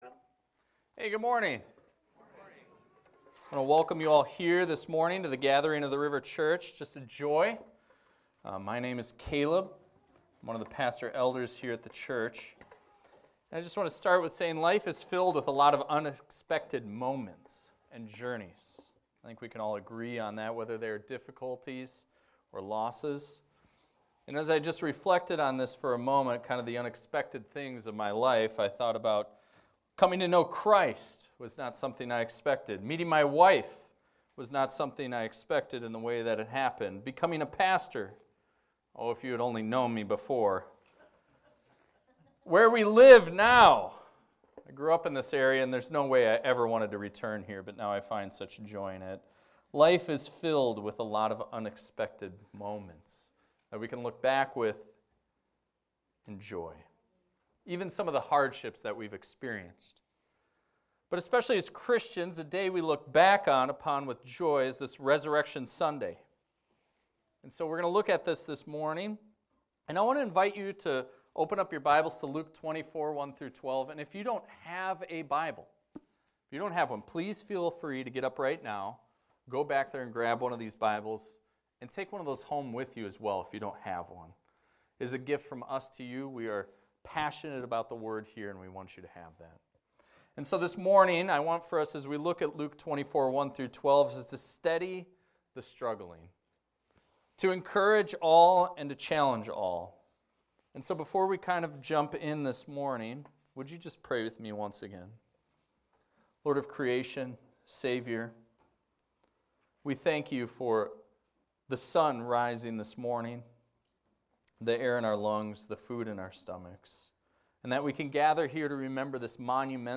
This is a recording of a sermon titled, "Jesus Told You."